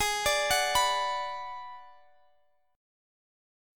Listen to Bm/G# strummed